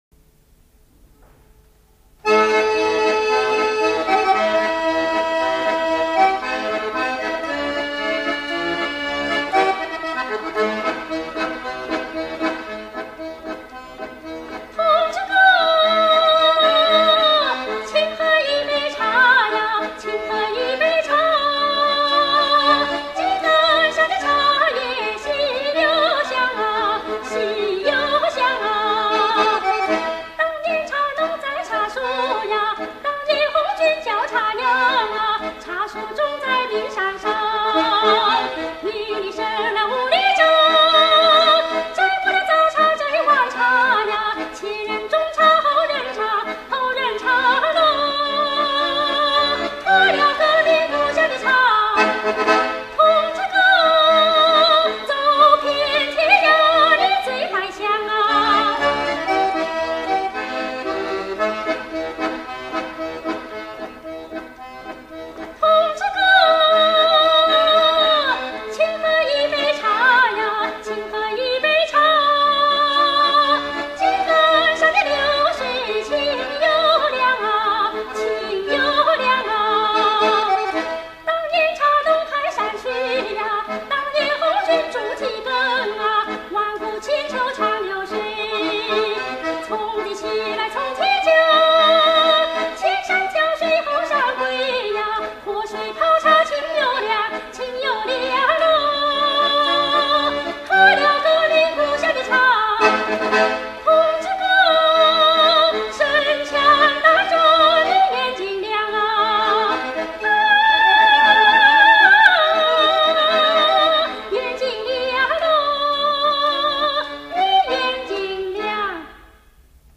1960年78转里的那个录音